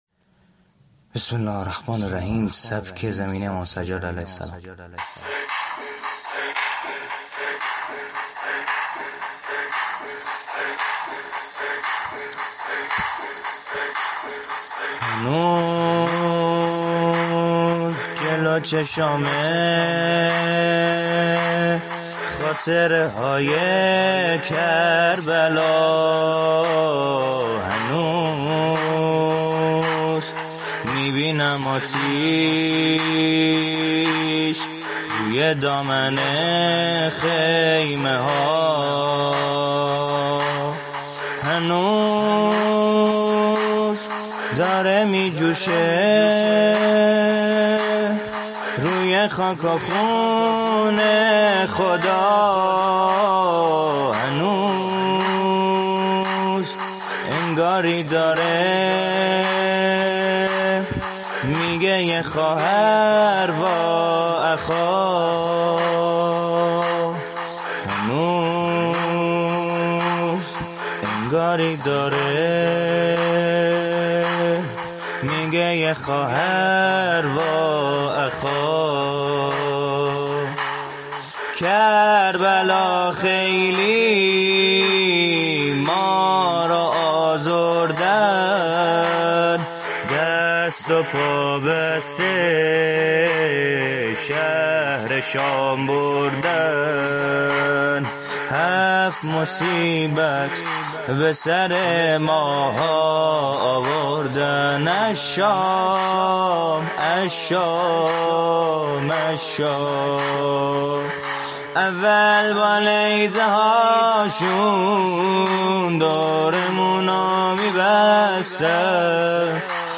سبک زمینه امام سجاد(ع) -(هنوز، جلو چشامه، خاطره های کربلا)